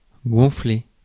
Ääntäminen
IPA : /ɪɡ.ˈzæs.pə.ˌreɪt/